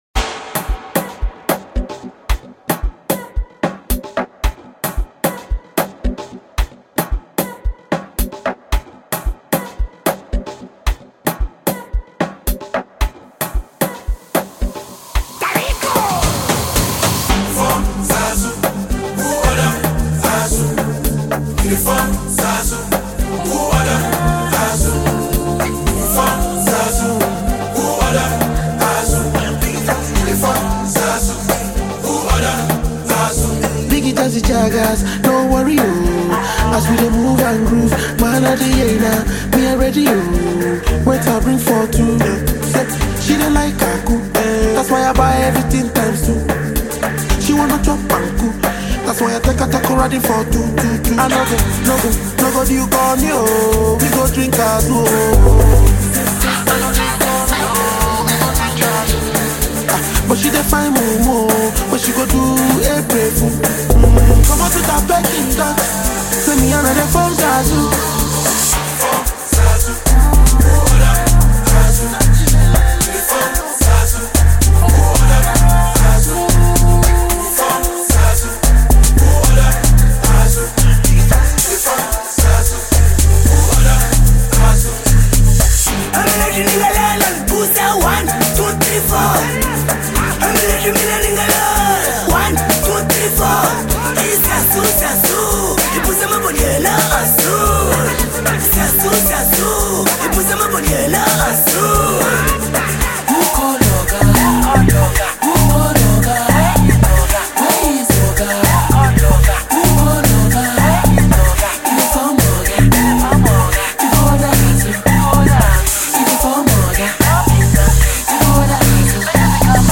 Enjoy this amazing Amapiano production.